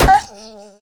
Minecraft Version Minecraft Version 1.21.5 Latest Release | Latest Snapshot 1.21.5 / assets / minecraft / sounds / mob / wolf / sad / hurt3.ogg Compare With Compare With Latest Release | Latest Snapshot
hurt3.ogg